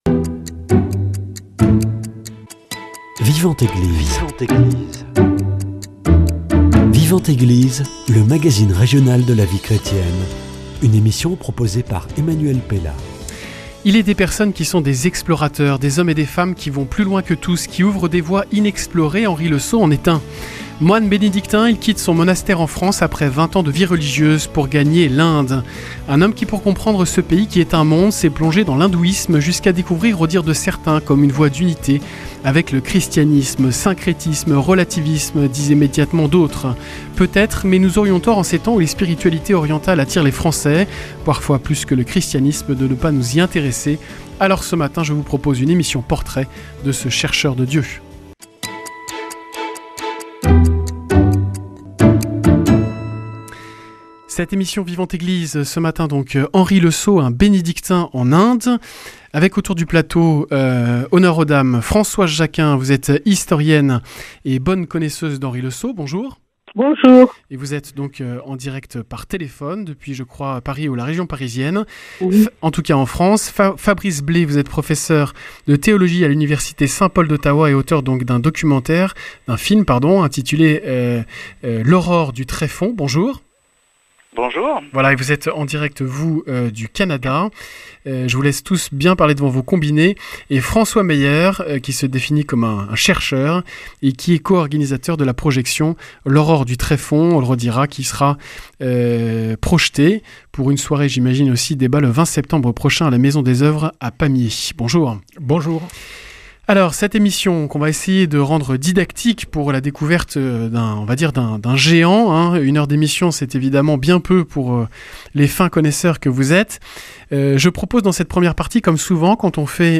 Portrait.